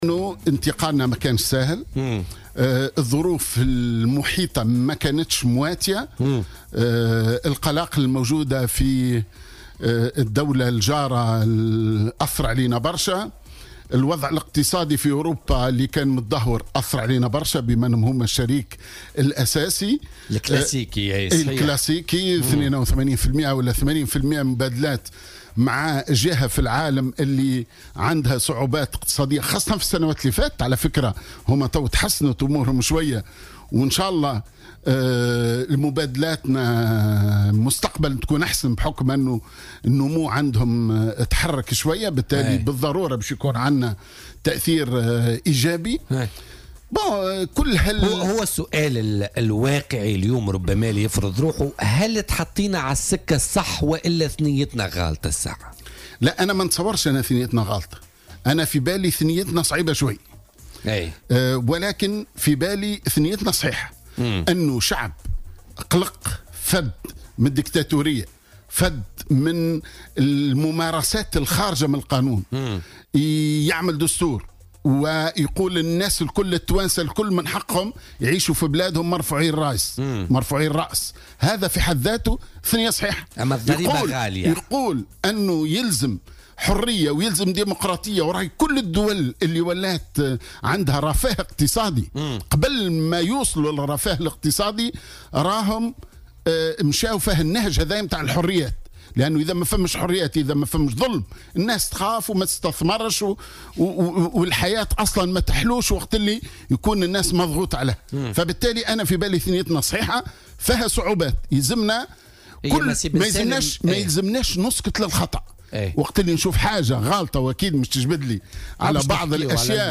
أكد القيادي في حركة النهضة محمد بن سالم ضيف بولتيكا اليوم الأربعاء أن الوضع الحالي يتميز بالصعوبة ولكن هناك عدة مبالغات معتبرا أن الديمقراطية ليست دائما طريقا مفروشا بالورود بل فيها بعض المساوئ.